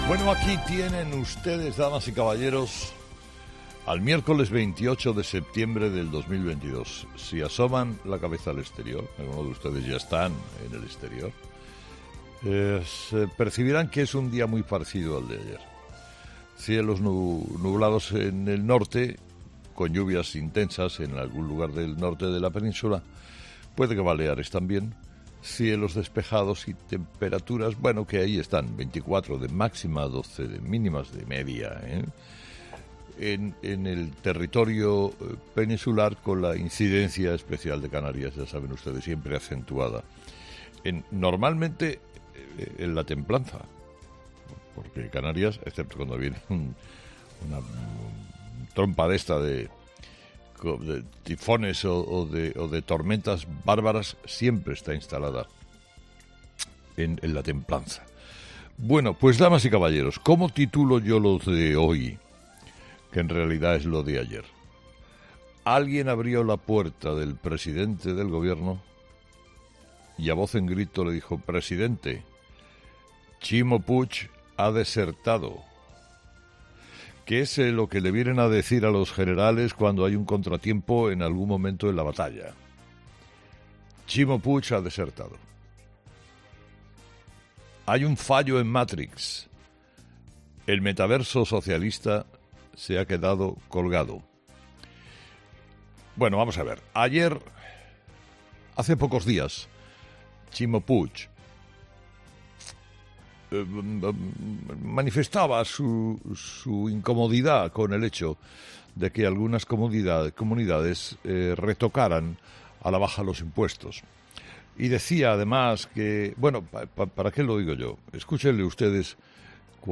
Carlos Herrera, director y presentador de 'Herrera en COPE', ha comenzado el programa de este miércoles analizando las principales claves de la jornada, que pasan, entre otros asuntos, por el impacto que ha tenido en el Gobierno de Pedro Sánchez el anuncio de Ximo Puig en materia de impuestos.